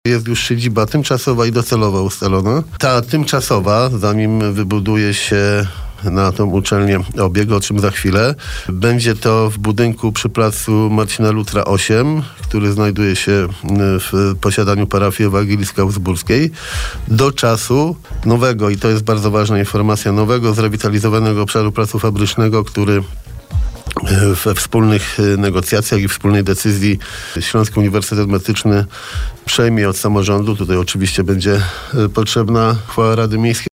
O to pytaliśmy prezydenta miasta.